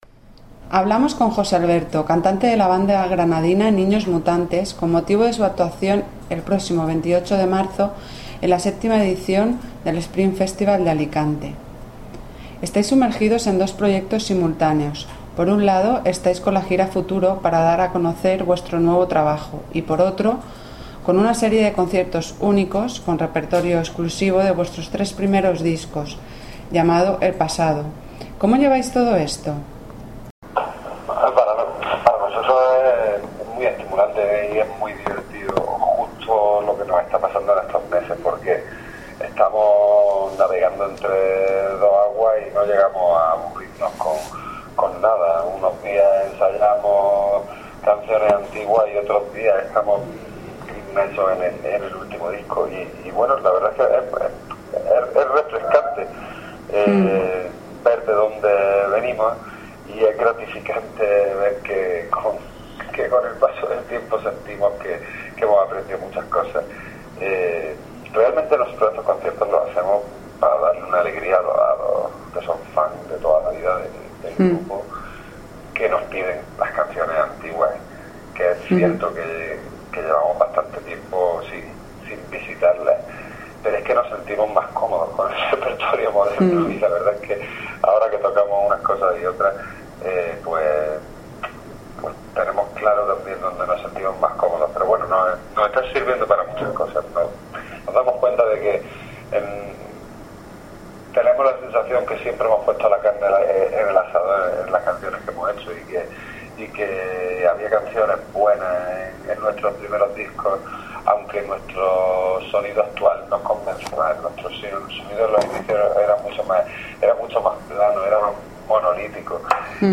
Audio y texto de la entrevista